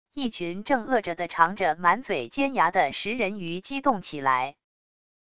一群正饿着的长着满嘴尖牙的食人鱼激动起来wav音频